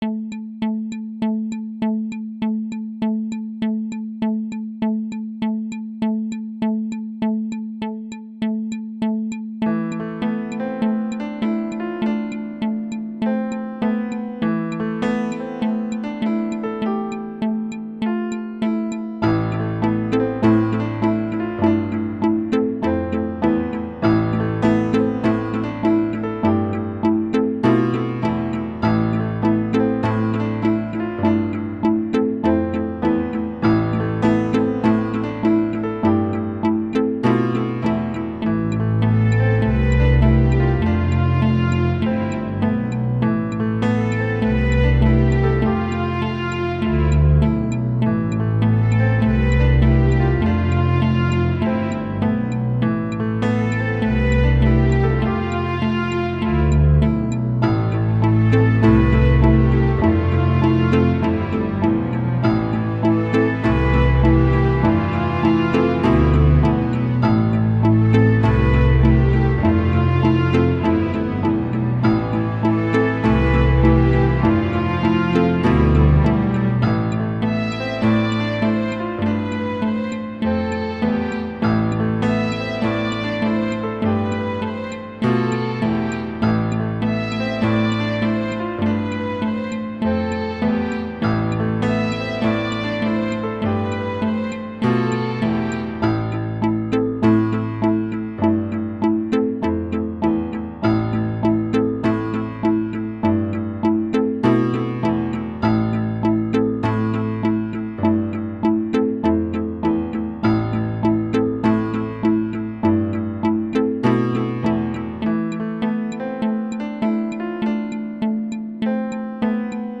And plinky plinky keyboards (sorry!)
My take was a bit more moody, but it could be that I’m working on a dark book right now.
sad-film.m4a